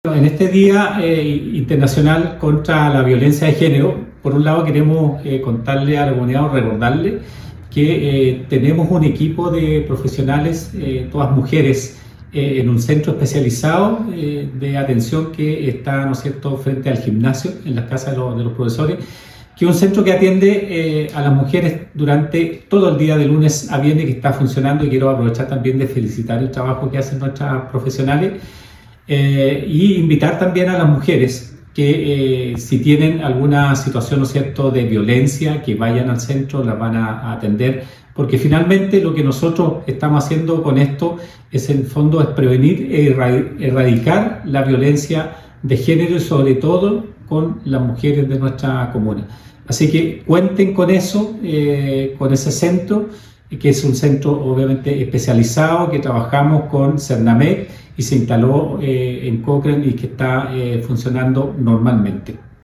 CUÑA ALCALDE PATRICIO ULLOA
CUNA-ALCALDE-PATRICIO-ULLOA.mp3